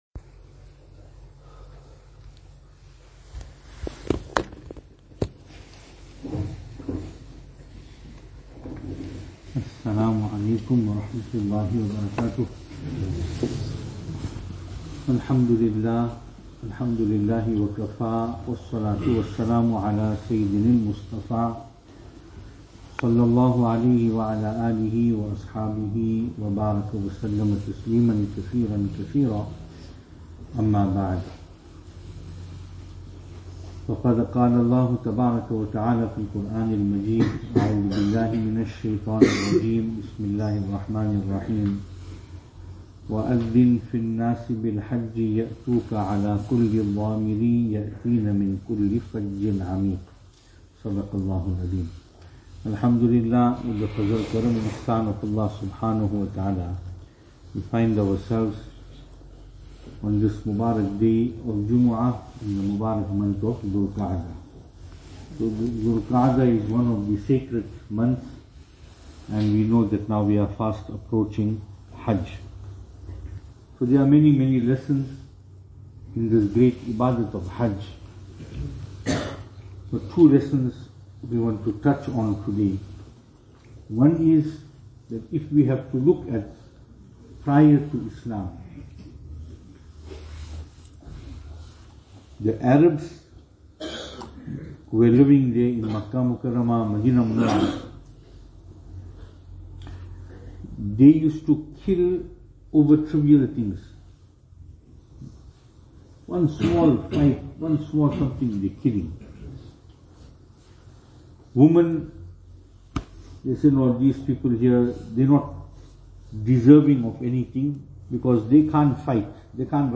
2023-05-26 Lessons To Take From Haj Venue: Albert Falls , Madressa Isha'atul Haq Service Type: Jumu'ah